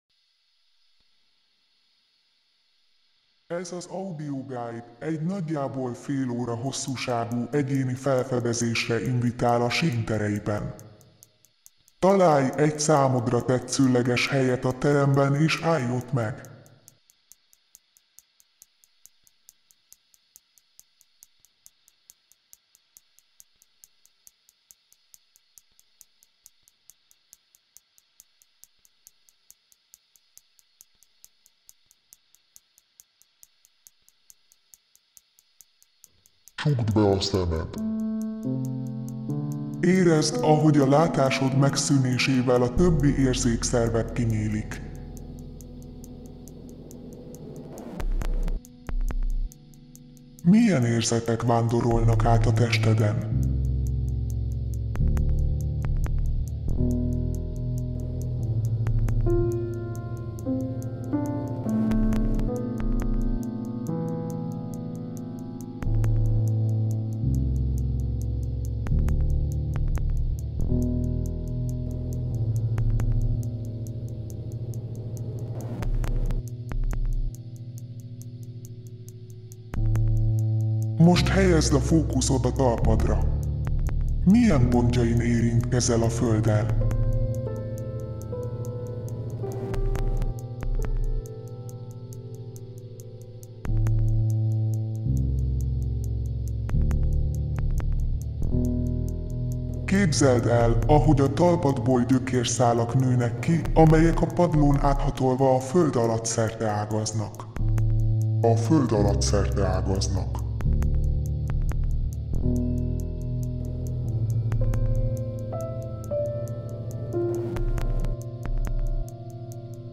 CA_IDNO en -00118 Title en Reverso Description en This is an audioguide to explore the building of SÍN Arts Centre.